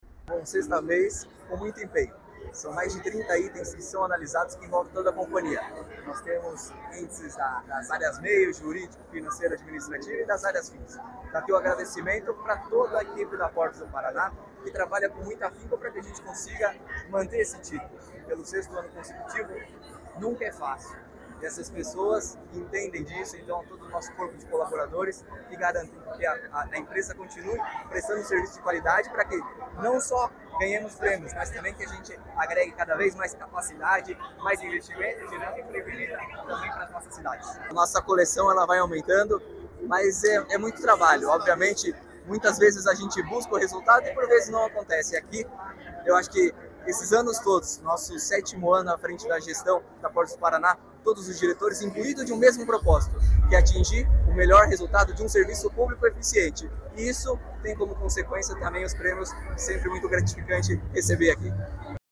Sonora do diretor-presidente da Portos do Paraná, Luiz Fernando Garcia, sobre a empresa ser hexacampeã do principal prêmio de gestão portuária do Brasil